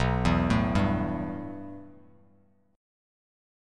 B7sus4#5 chord